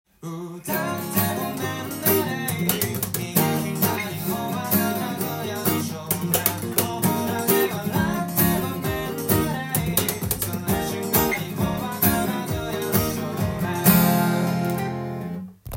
音源に合わせて譜面通り弾いてみました
ギターパートは、スラップギターと言われる
叩いて音を出しながら、打撃音も出す奏法を使用しています。
カポタストを１フレットにつけて演奏していきます。
C、D、Emになります。